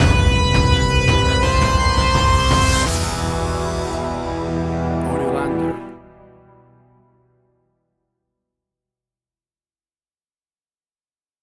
WAV Sample Rate: 16-Bit stereo, 44.1 kHz
Tempo (BPM): 84